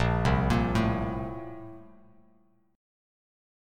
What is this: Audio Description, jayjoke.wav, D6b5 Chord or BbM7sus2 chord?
BbM7sus2 chord